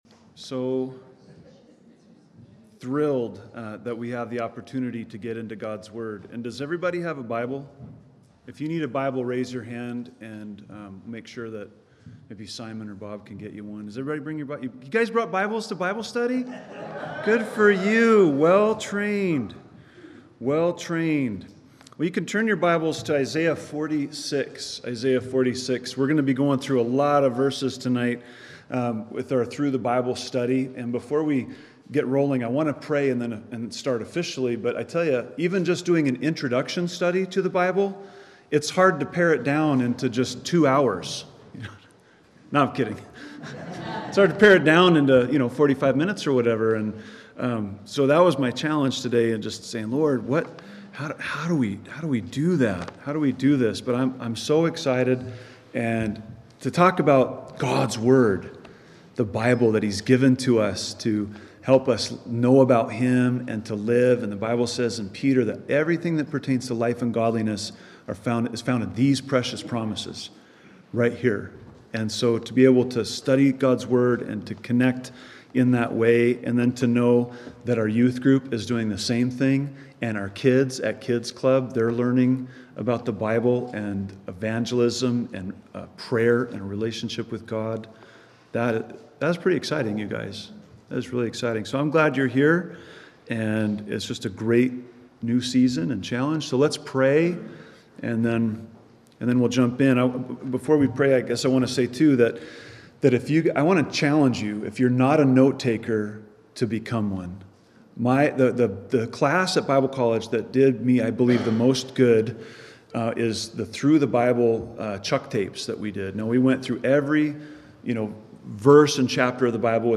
An overview of the entire Bible in one year. Wednesday night study.